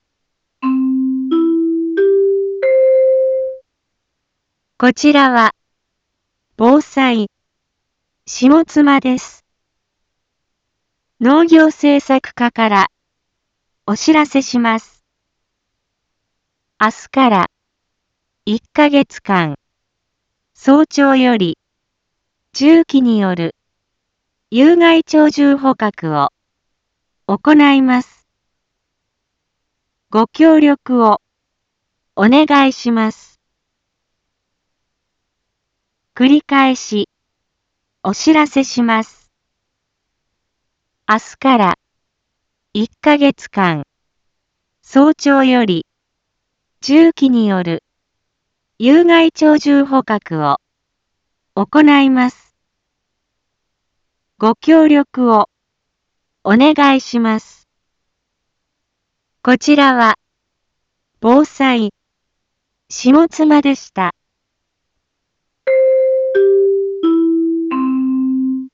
一般放送情報
Back Home 一般放送情報 音声放送 再生 一般放送情報 登録日時：2025-05-23 18:31:26 タイトル：有害鳥獣捕獲の実施についてのお知らせ インフォメーション：こちらは、ぼうさい、しもつまです。